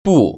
[bù]